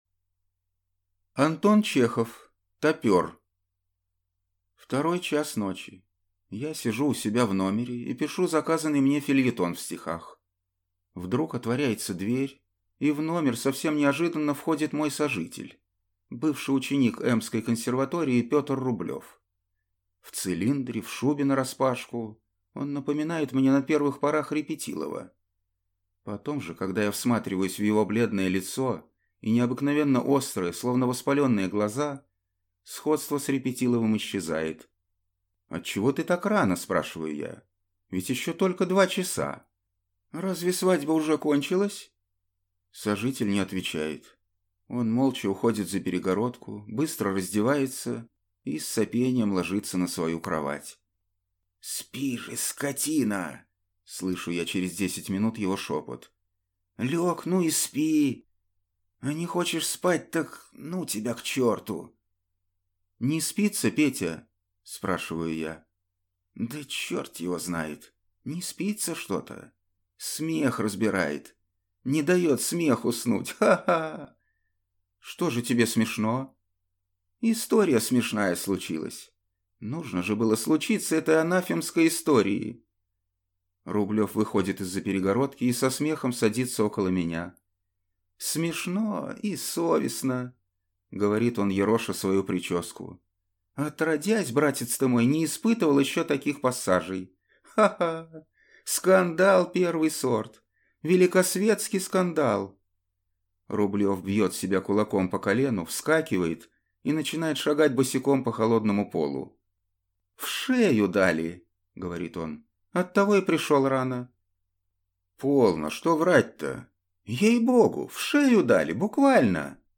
Аудиокнига Тапер | Библиотека аудиокниг